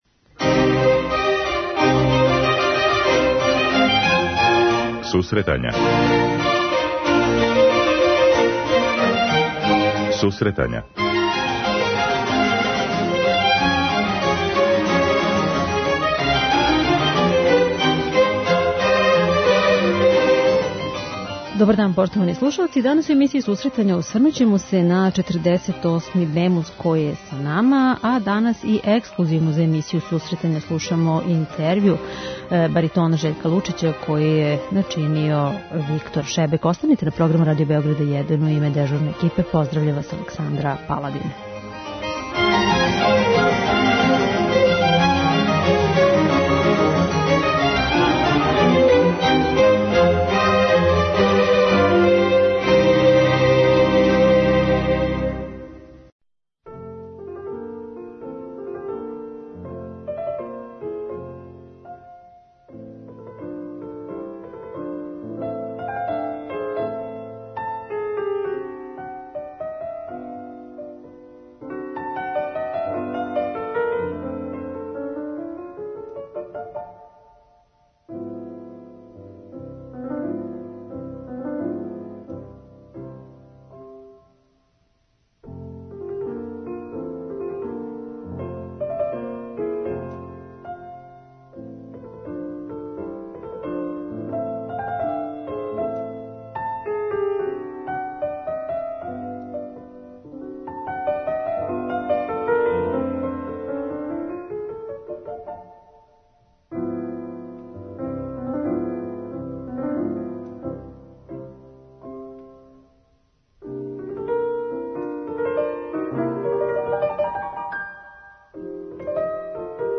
Данас у емисији емитујемо ексклузивни интервју са баритонон Жељком Лучићем